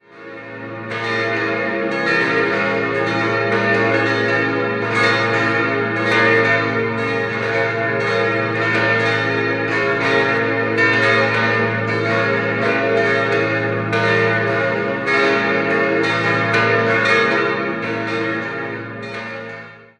In den Jahren 1934/35 wurde das heutige Gotteshaus nach den Plänen des Züricher Architekten Fritz Metzger errichtet. 5-stimmiges Geläut: b°-des'-f'-as'-b' Die Glocken wurden 1935 von der Gießerei Rüetschi in Aarau gegossen.